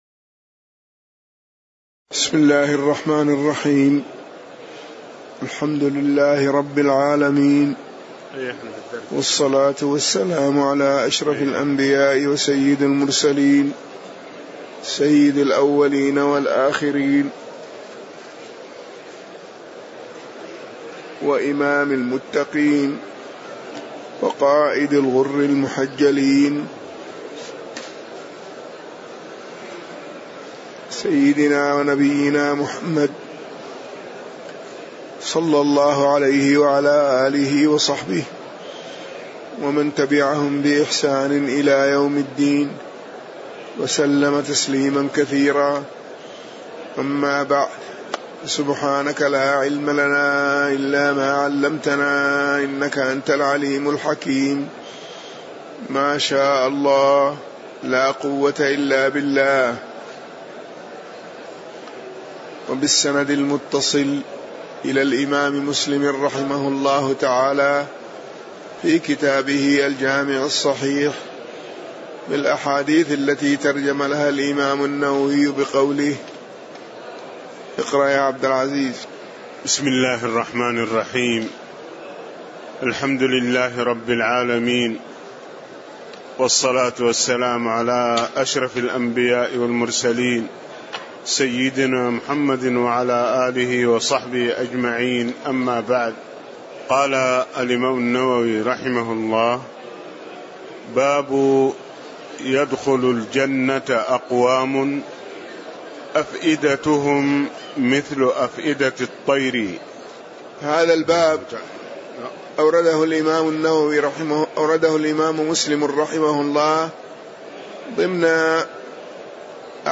تاريخ النشر ٢١ جمادى الآخرة ١٤٣٨ هـ المكان: المسجد النبوي الشيخ